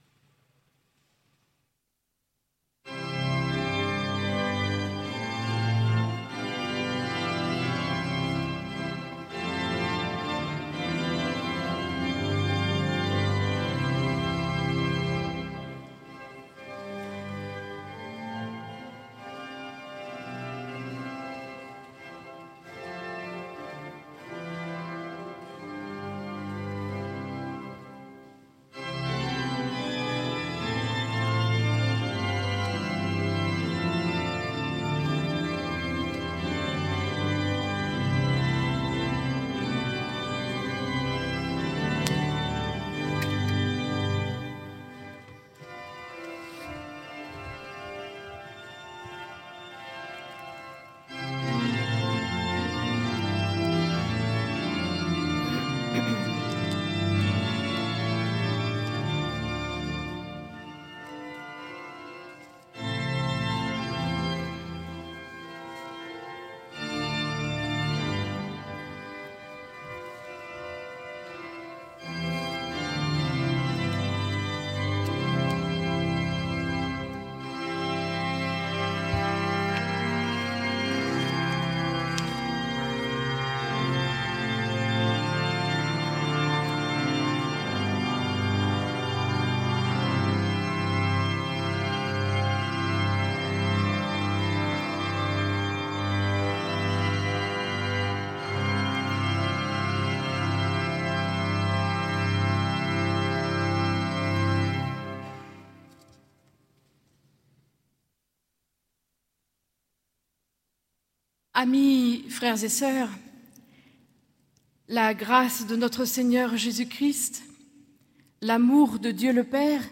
Écouter le culte entier (Télécharger au format MP3)